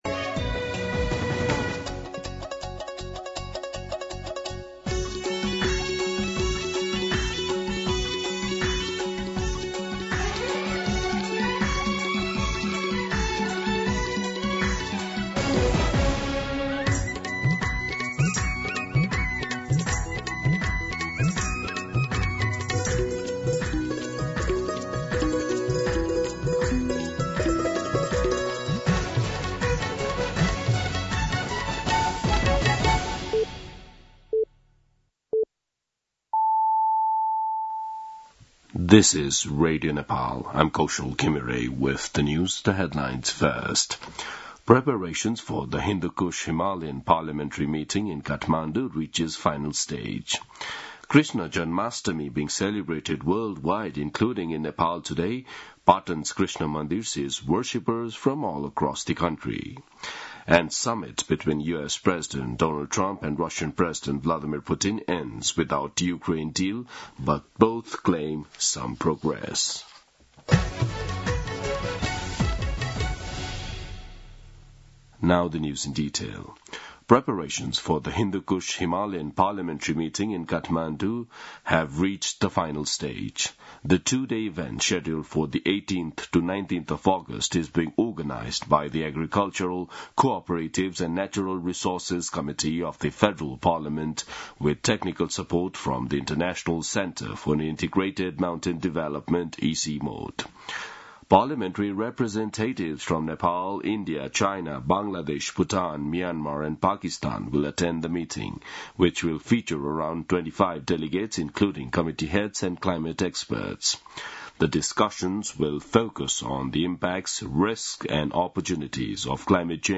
दिउँसो २ बजेको अङ्ग्रेजी समाचार : ३१ साउन , २०८२
2-pm-English-News-04-31.mp3